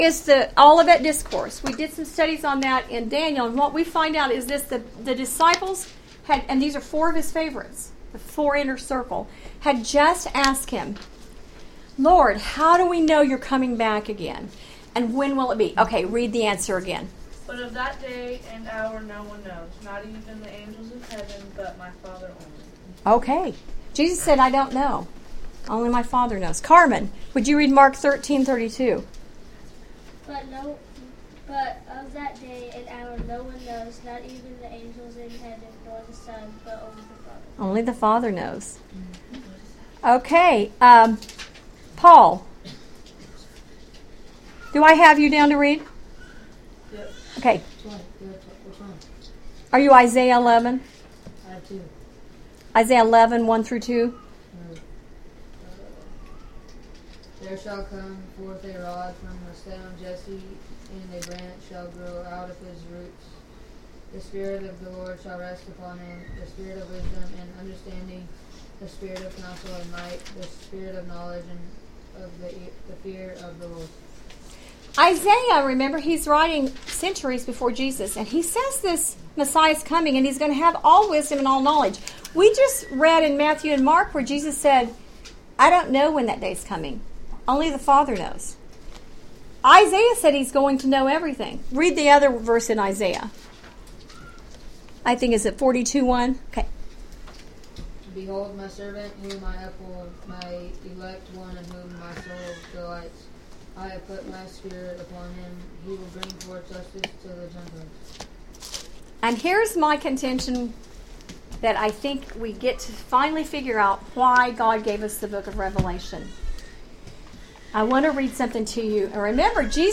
Bible Study Audio and Worksheets Revelation Chapter 1 June 26